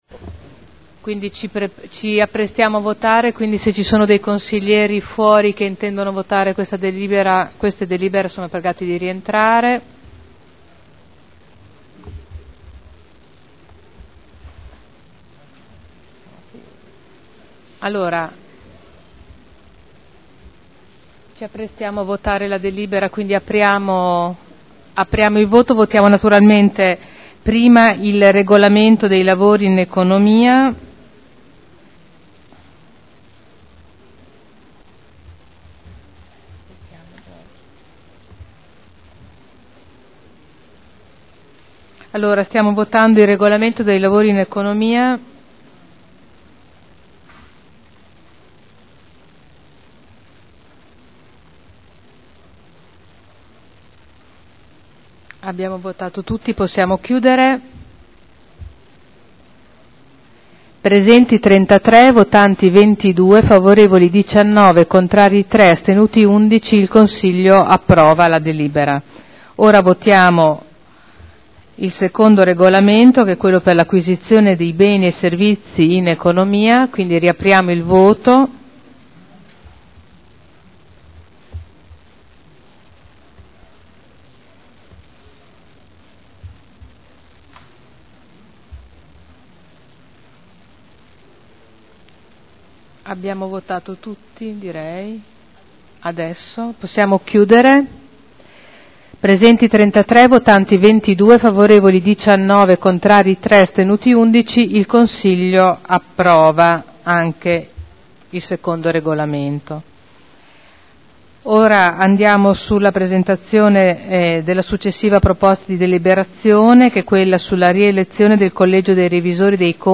Seduta del 12/03/2012. Mette ai voti le Delibere.